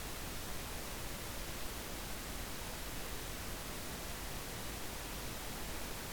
before-silence.wav